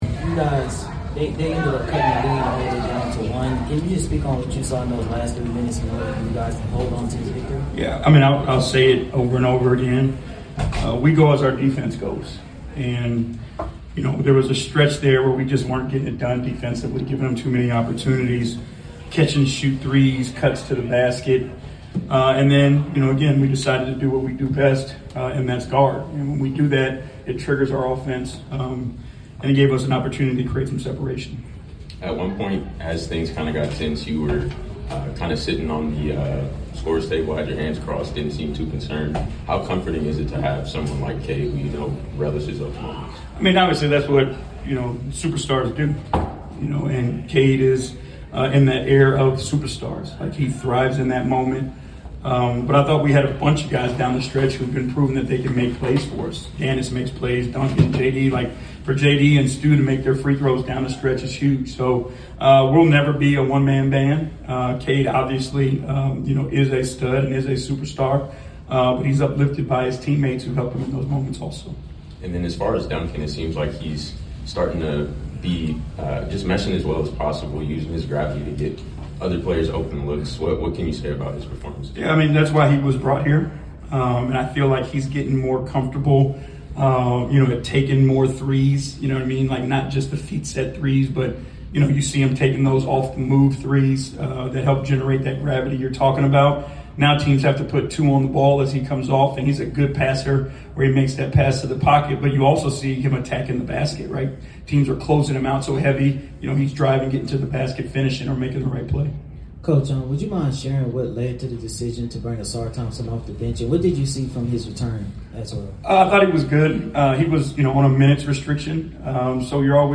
Detroit Pistons Coach J.B. Bickerstaff Postgame Interview after defeating the Atlanta Hawks at State Farm Arena.